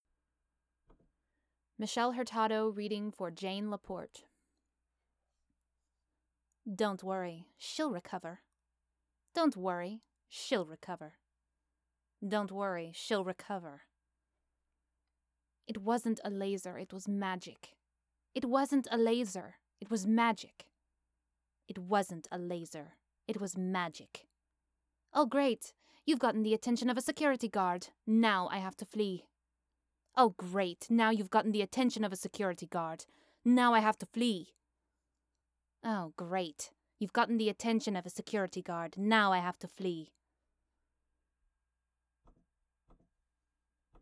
For Jane I'm looking for a low to mid-ranged female voice. Jane's also from a bilingual household, so she as a slight French Canadian accent too.
This is the voice she has had in the show so far.